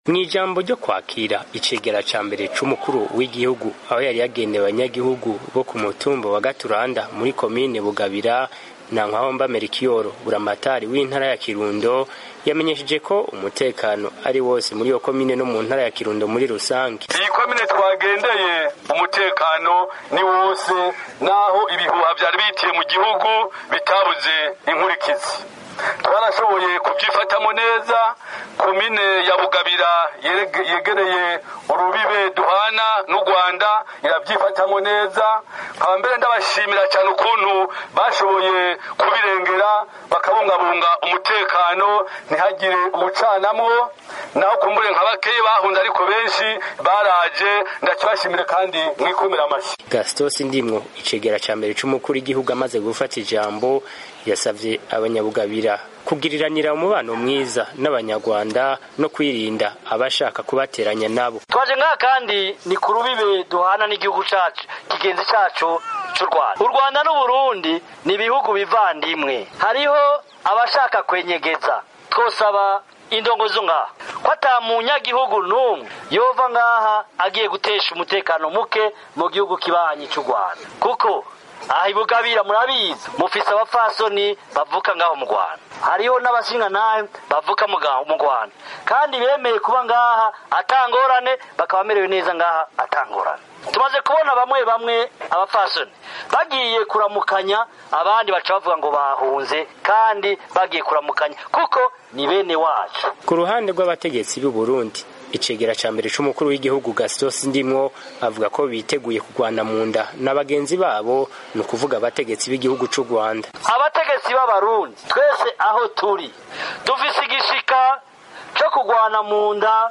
Ivyo yabivugiye imbere y’abanyagihugu bo ku mutumba wa Gaturanda muri komini Bugabira, intara ya Kirundo, ku mupaka w’Uburundi n’Urwanda aho yari yabagendeye.